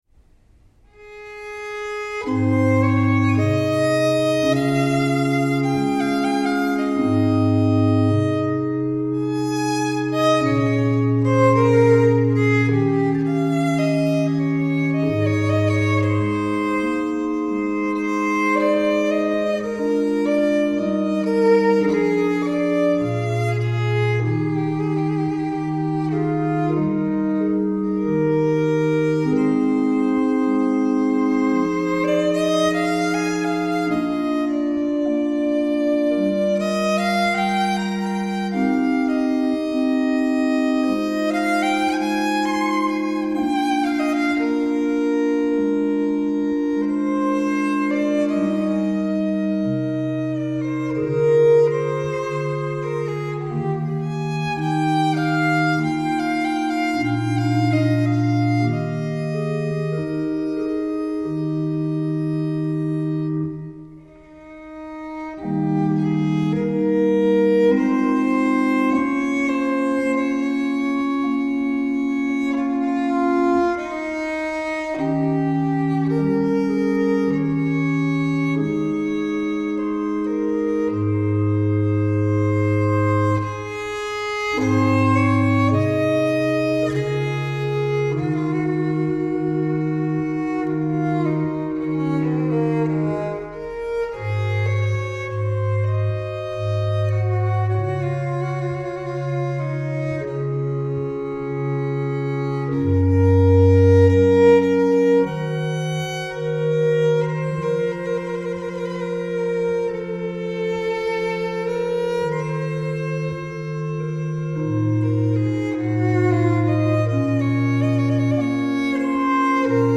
A few weeks ago, I dropped in on the Tartini ensemble during a rehearsal in St Mary’s church, Penzance.
It was also the first outing for the baroque violin that I wrote about a couple of posts ago. After the rehearsal
chamber organ. Tartini adagio